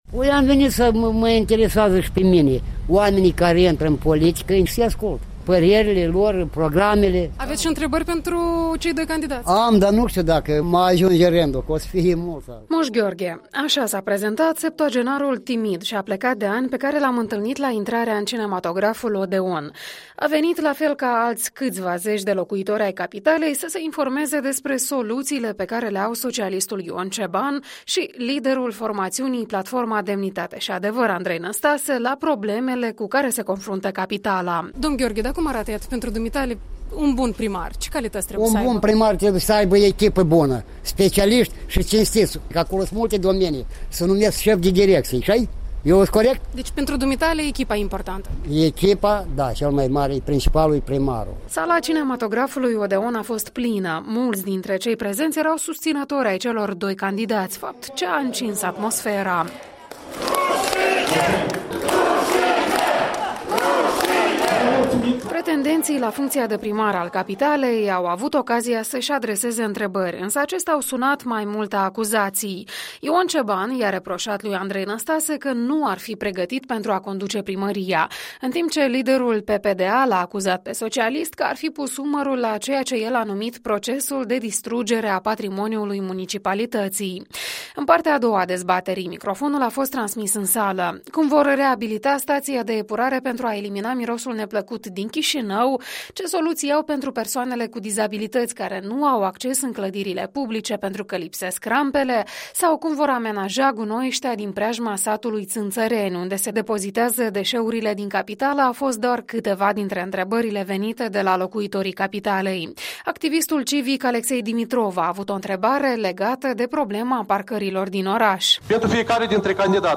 Dezbaterea publică de marţi seara a fost singura din ajunul celui de-al doilea scrutin pentru şefia capitalei. Sala cinematografului Odeon a fost plină, însă mulţi dintre cei prezenţi erau susţinători ai celor două tabere, fapt care a încins atmosfera.
În partea a doua a dezbaterii microfonul a fost transmis în sală.